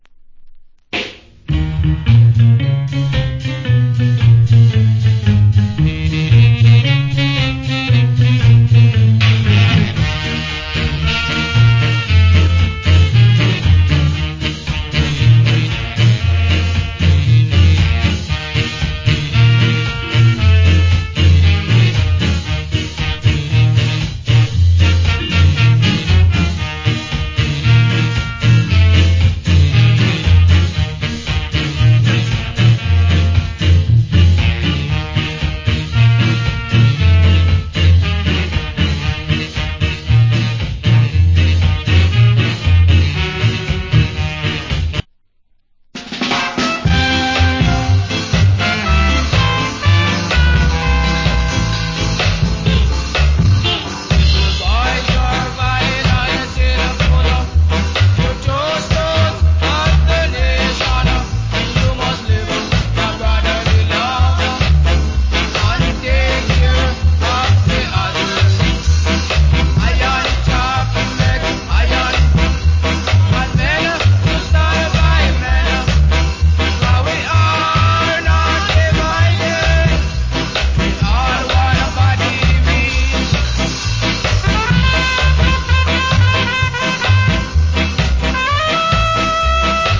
Killer Ska Inst.